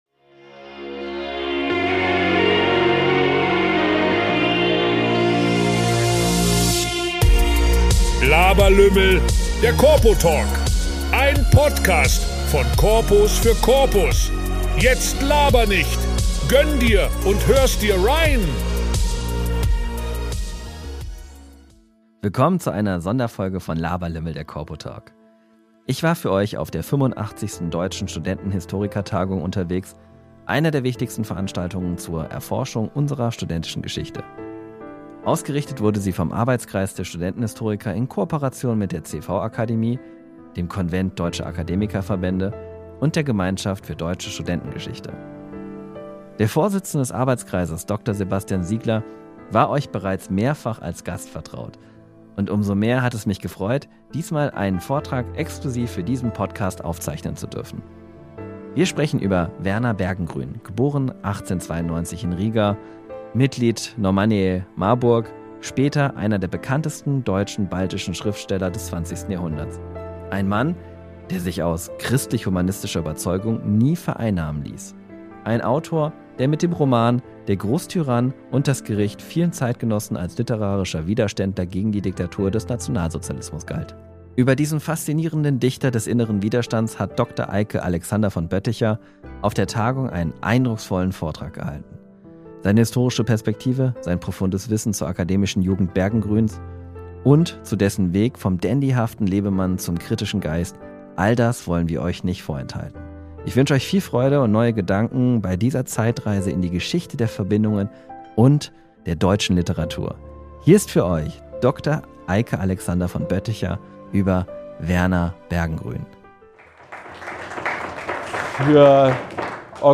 Sonderfolge von der 85. Studentenhistorikertagung